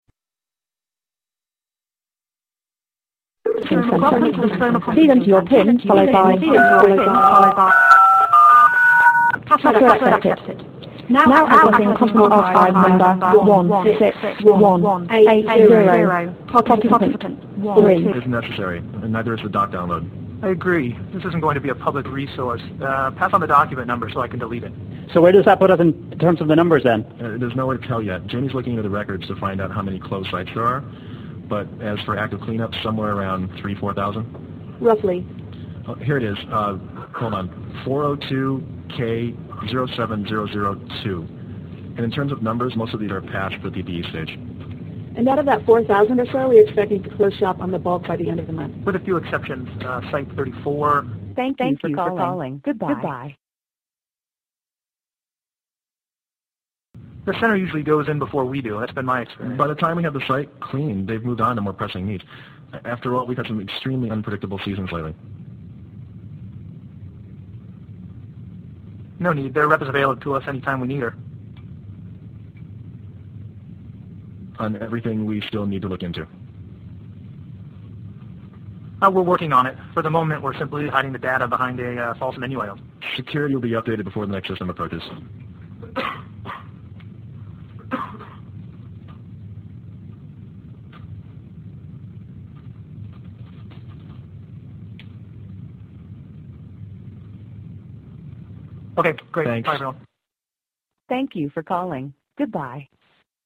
All voices of flood containment control number mixed and dubbed to current FULL length of side A and B.